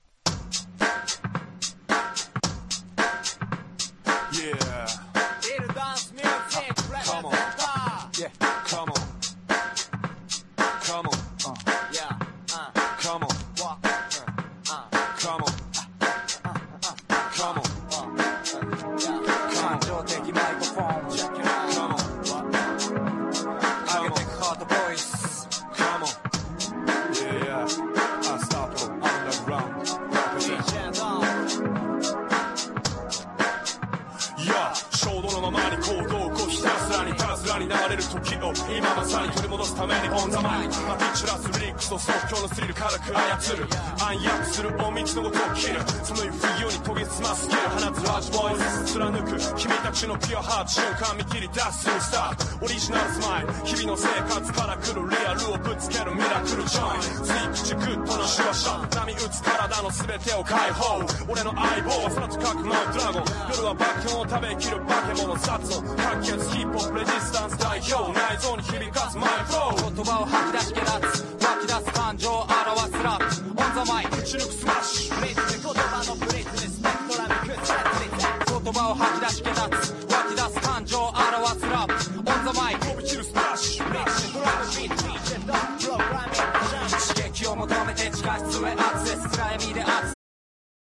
HIPHOP# UNDERGROUND